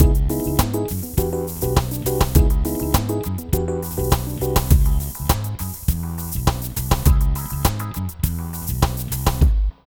Ala Brzl 1 Fnky Full-C#.wav